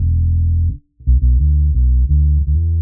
Index of /musicradar/dub-designer-samples/85bpm/Bass
DD_PBass_85_E.wav